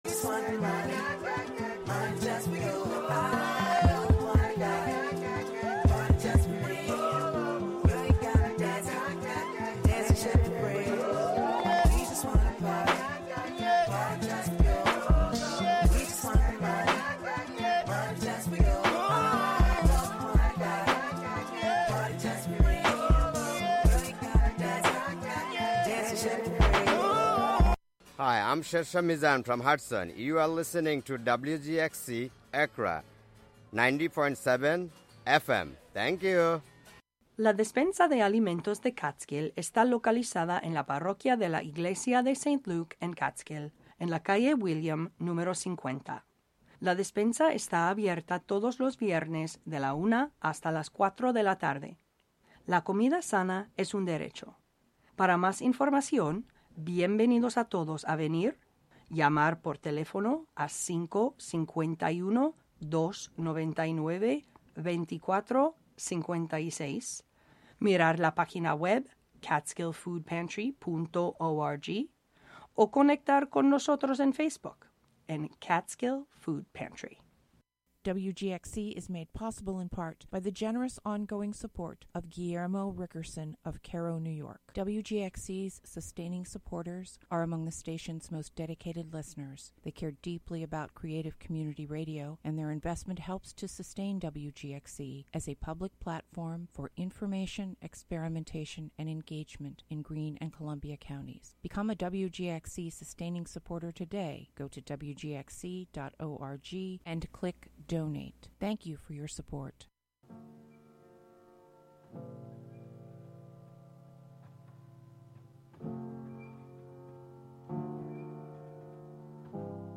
Show includes local WGXC news at beginning, and midway through.